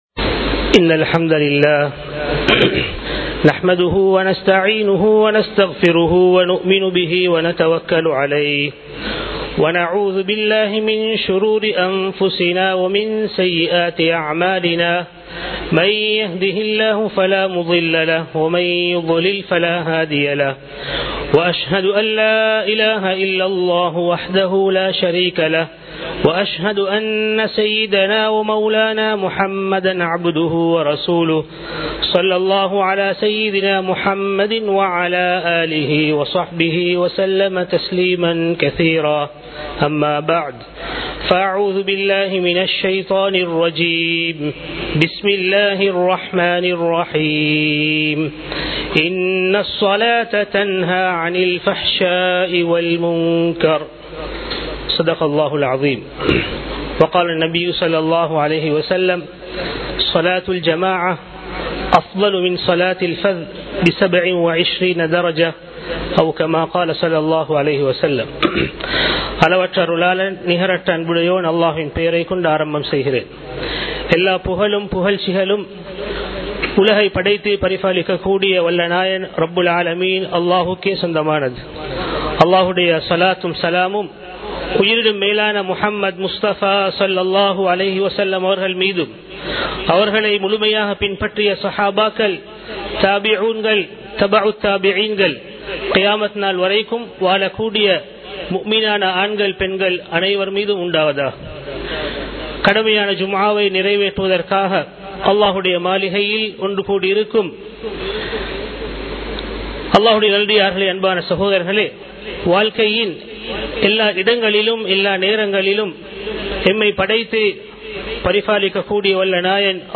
தொழுகையின் முக்கியத்துவம் | Audio Bayans | All Ceylon Muslim Youth Community | Addalaichenai
Muhiyadeen Jumua Masjith